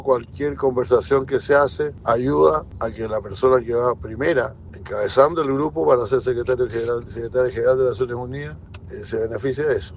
Por su parte, el senador socialista y excanciller José Miguel Insulza valoró las gestiones internacionales del presidente Gabriel Boric en favor de Bachelet.